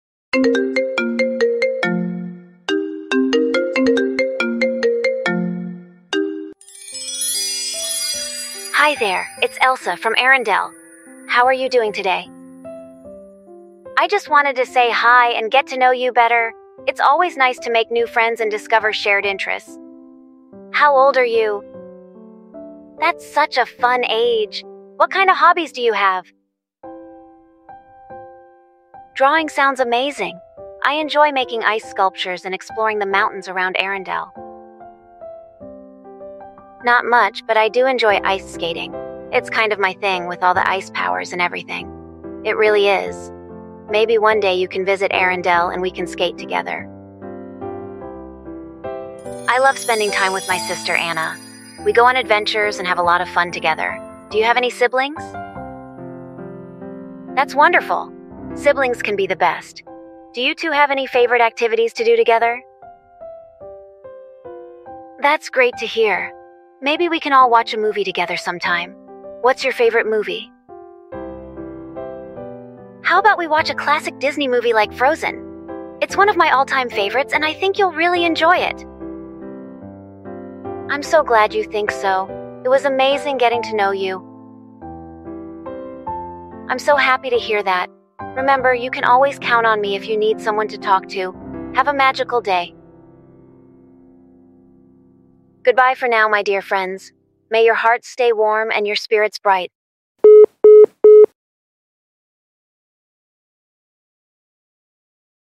👑✨ In this magical fake call, Elsa reaches out with fun surprises, icy twists, and a little Frozen magic!